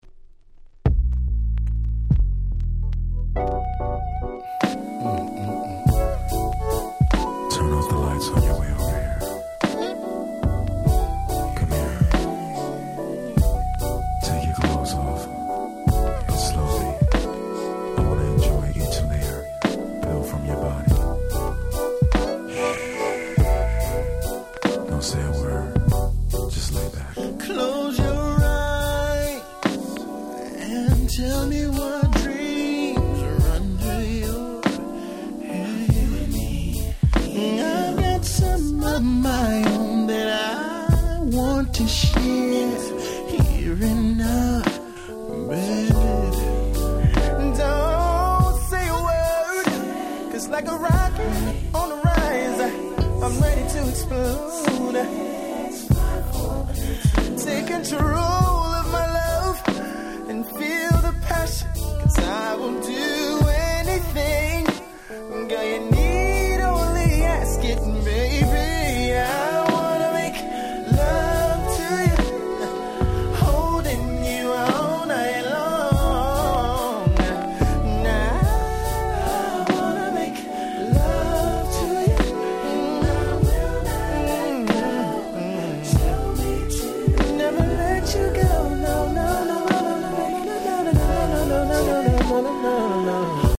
95' Super Hit Slow Jam !!
95年の世界的大ヒットバラード！
こちらはPromo OnlyとなるRemixで、オリジナルより甘さ控え目でGroovyな好Remix !!